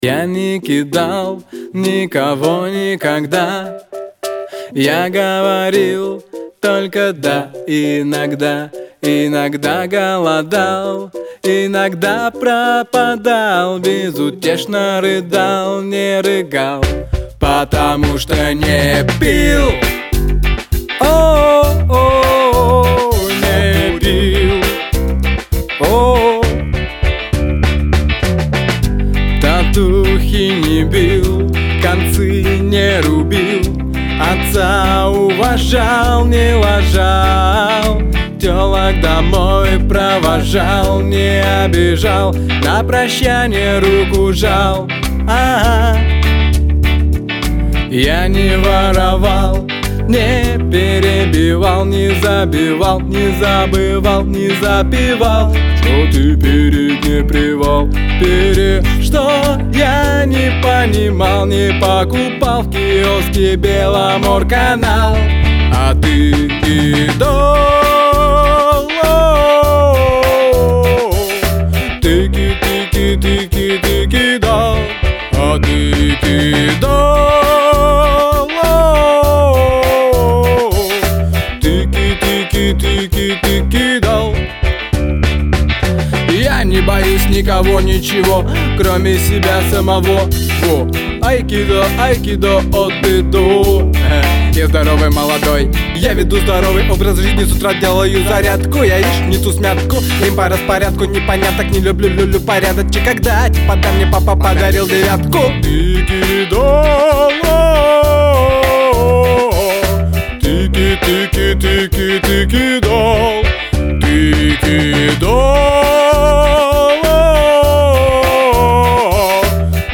Прошу оценить сведение
Вокал не чистил, вокалиста не бил.